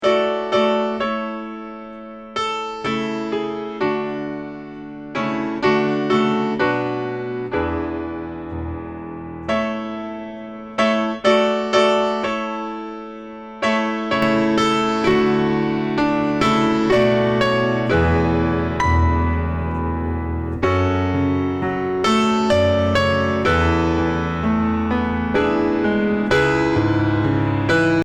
la sonorité est bien chaleureuse et les distorsion (si on cherche à en obtenir par l'émulation de lampe ou par le compresseur) restent très musicales avec des harmoniques qui n'agressent pas
voici quelques exemple (fait en deux minutes avec les presets) vous avez toujours au départ le son d'origine et en deuxième partie le son traité avec le channel-strip Nomad
un piano - j'ai troop appuyé sur la compression, sorry !! hihi
790piano.mp3